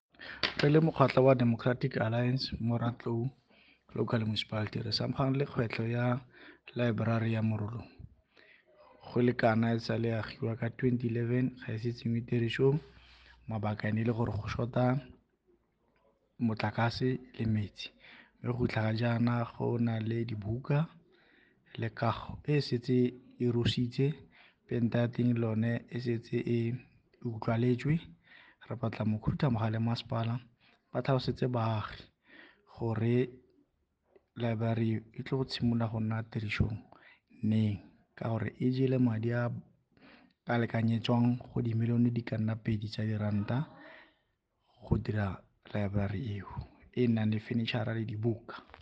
Note to Broadcasters: Please find linked soundbites in
Setswana by Cllr Aubrey Radebe.